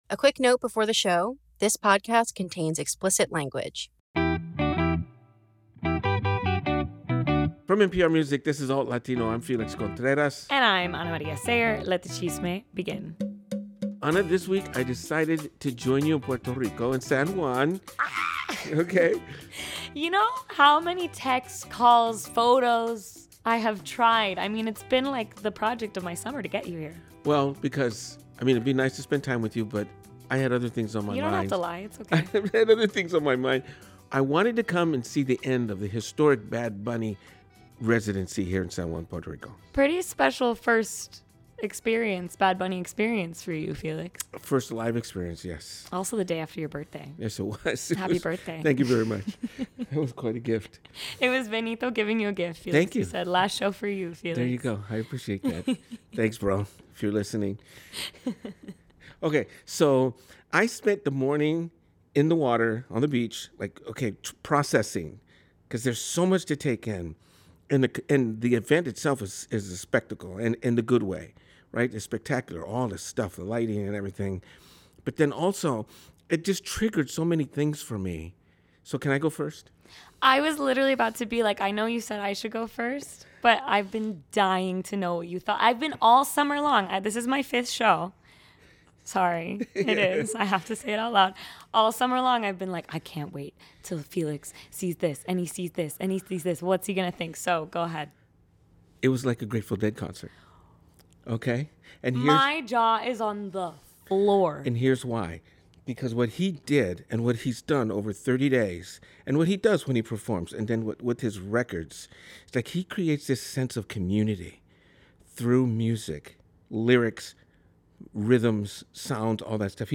traveled to San Juan to take in one of the final performances, and interview both locals and tourists about the musical and cultural impact of the summer of Bad Bunny.